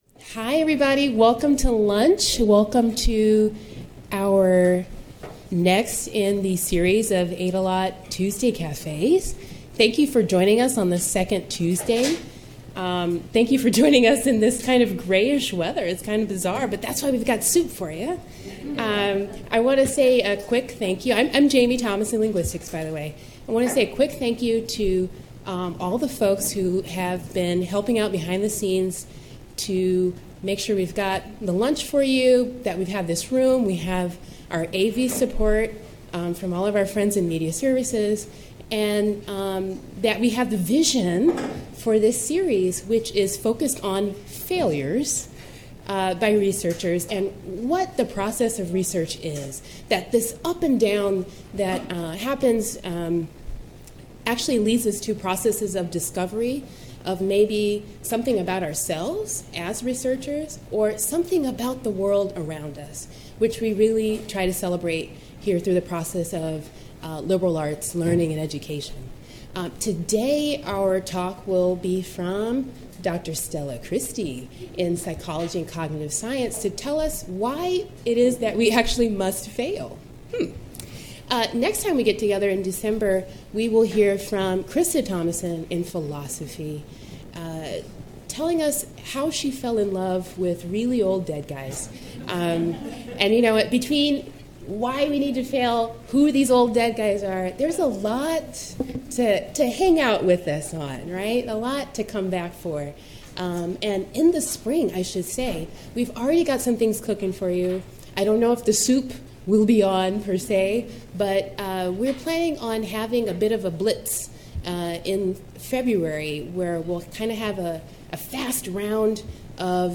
In this Aydelotte Foundation talk, Christie argues that failure can be a logical necessity in psychology.